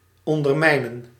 Ääntäminen
IPA : /ʌndəˈmʌɪn/